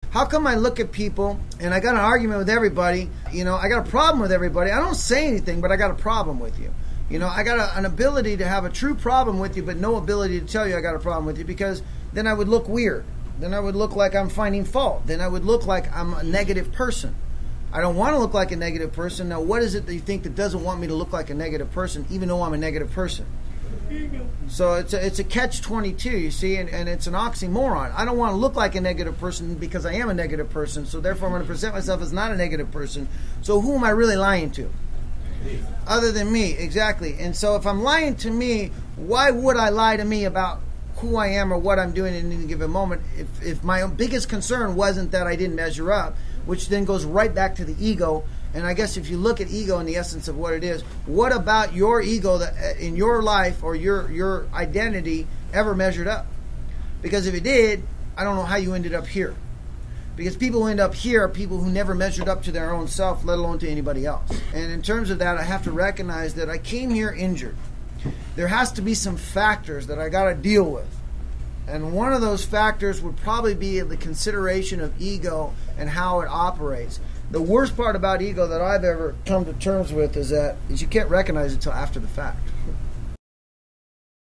This audio archive is a compilation of many years of lecturing.
Within the lectures, you will hear people ask questions about why am I where I am, how can I get to a better place and what is blocking me.
A discussion on how ego distorts reality, fuels resentment, and blocks growth, and how paths 2, and 3 can help us shift from ego-driven reactions to spiritual connection and humility.